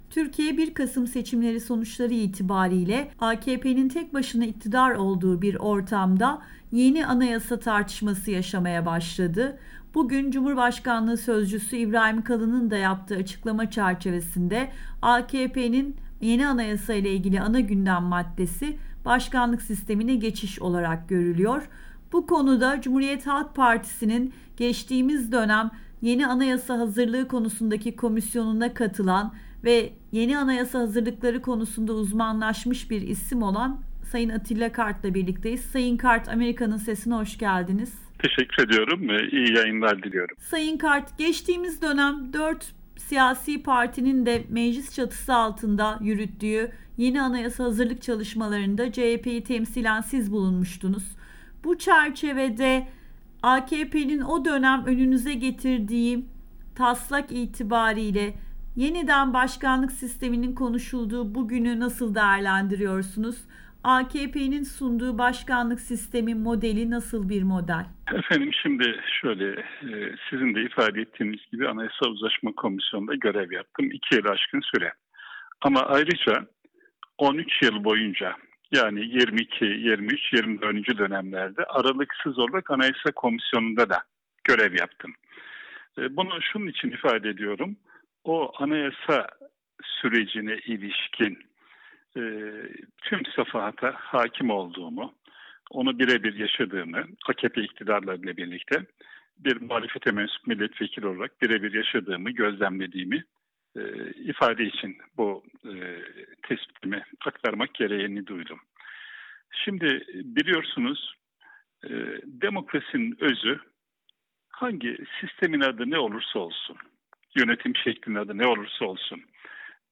Atilla Kart'la söyleşi